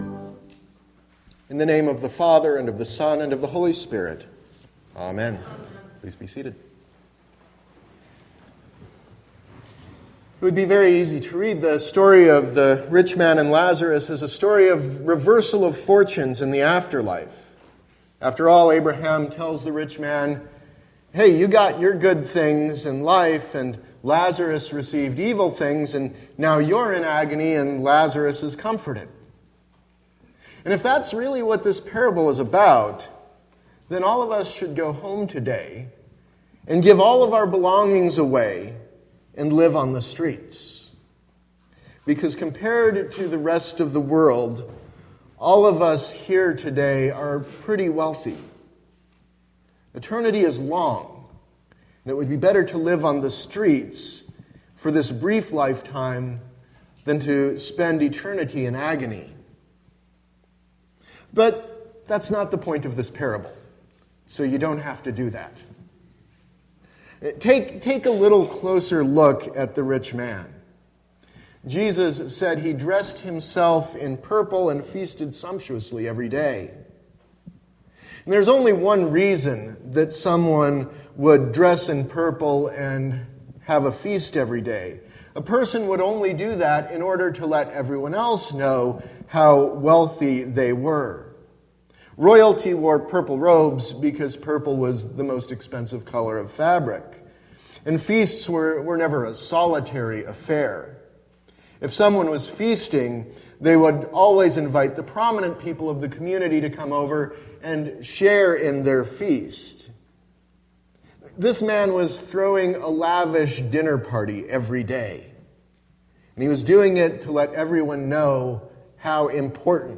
Sermon – September 25, 2016 – Advent Episcopal Church